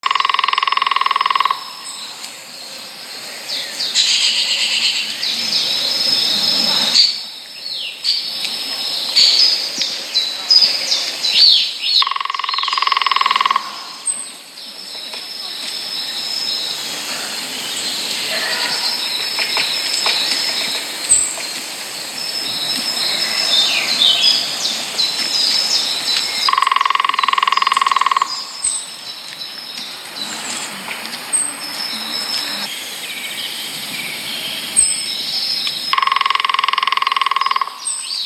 Ghionoaia verde (Picus viridis)
• Cântă un sunet amuzant, ca un râs puternic – o recunoști ușor în pădure.
Ghionoaie-verde.m4a